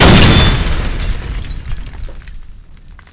explode_3.wav